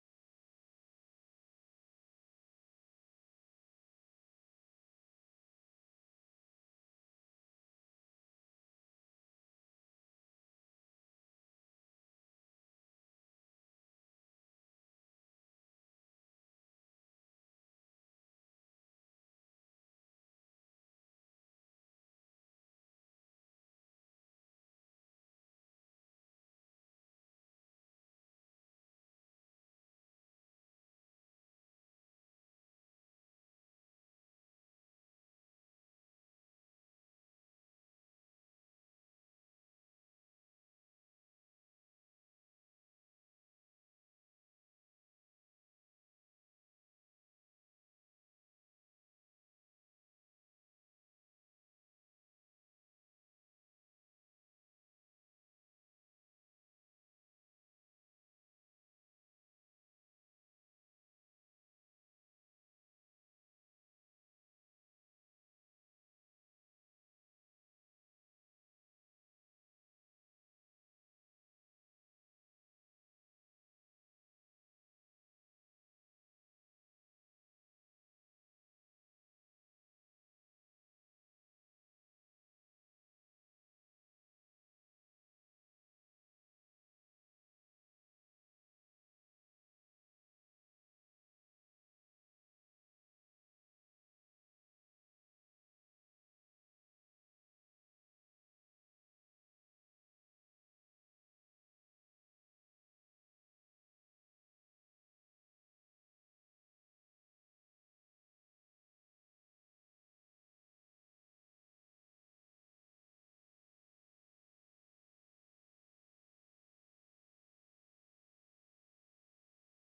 This is a podcast of City of Madison, Wisconsin Finance Committee meetings. The Finance Committee makes recommendations regarding budget amendments and other matters with significant fiscal implications during the year.